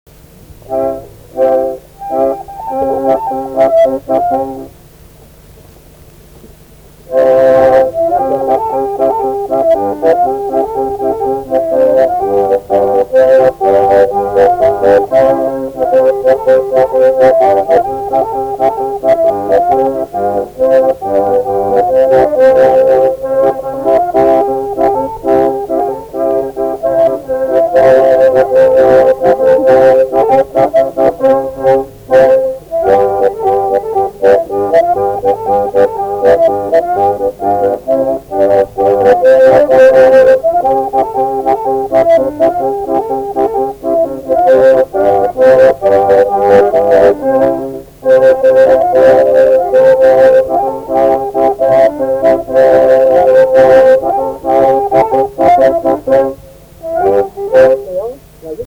šokis